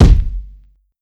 Waka KICK Edited (45).wav